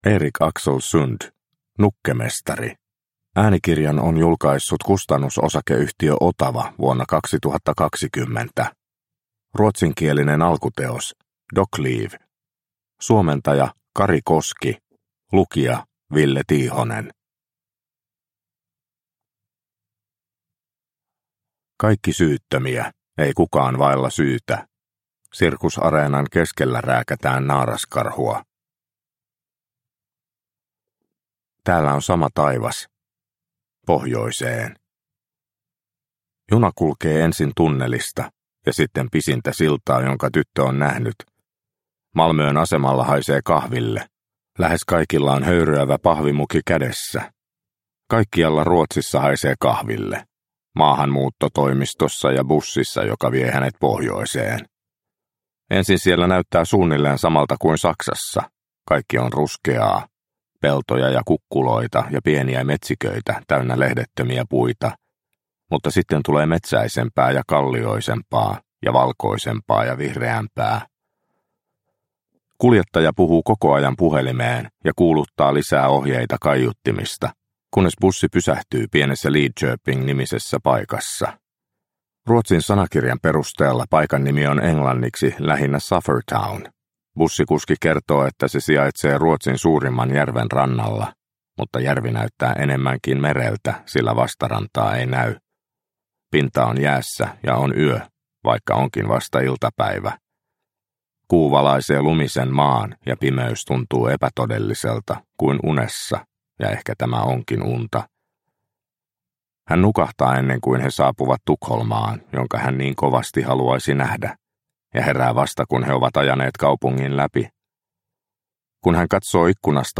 Nukkemestari – Ljudbok – Laddas ner